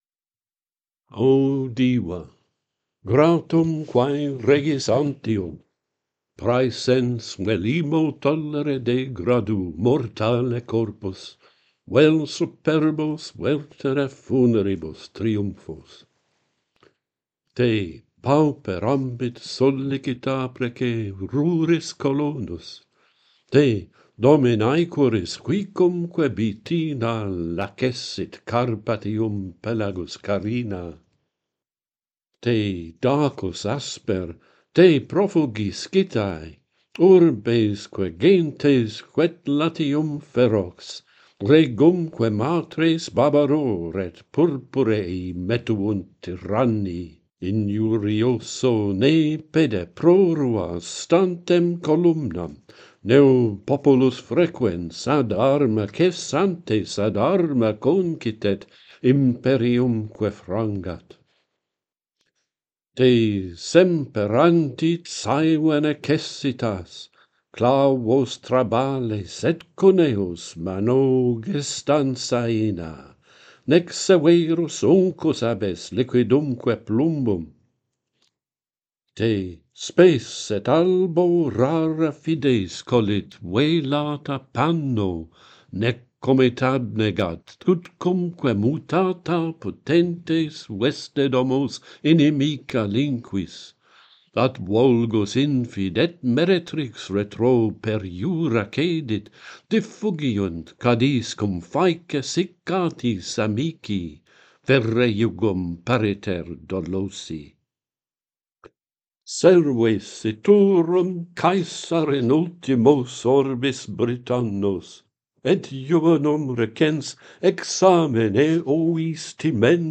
Fortuna - Pantheon Poets | Latin Poetry Recited and Translated
The metre is Alcaics.